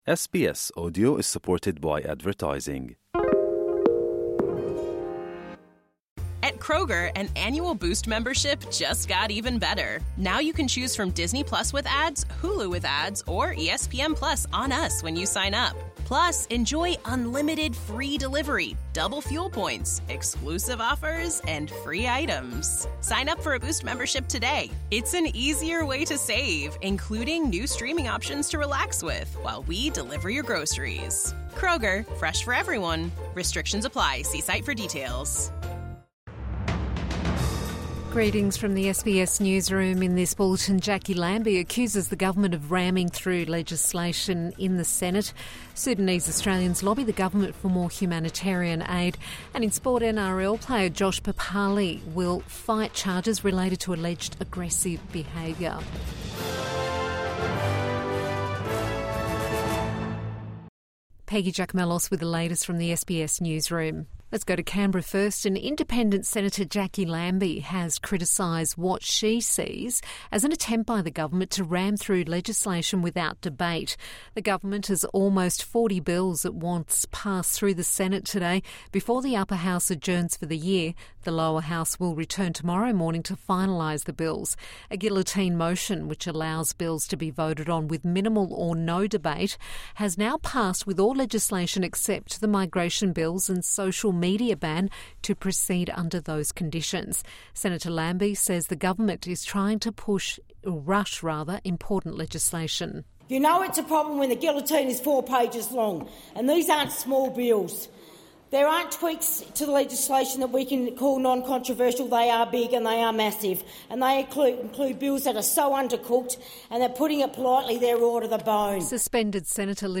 Evening News Bulletin 28 November 2024